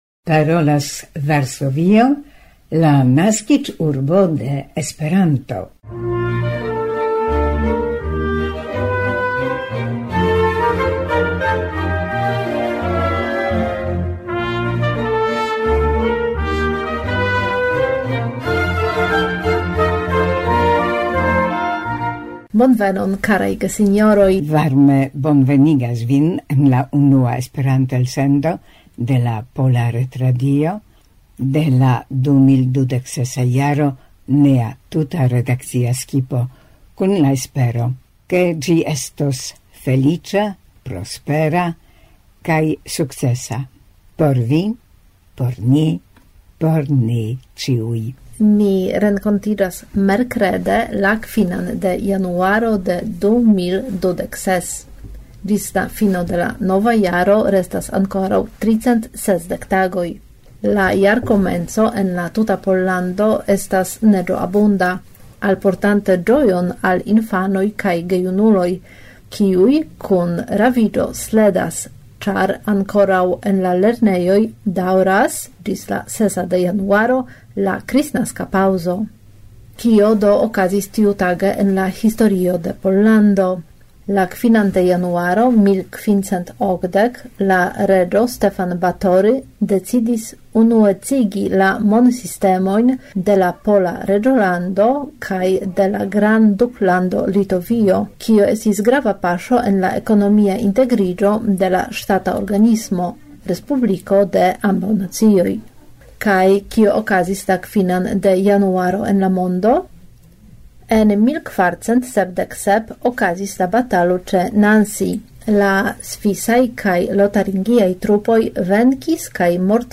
• En la kulturkronika rubriko ni parolas pri la novaranĝita Artgalerio de la Eŭropa Arto en Krakovo, pri ekspozicio kolektinta tolaĵojn de polaj artistoj aktivantaj en la t.n. Munkena Skolo.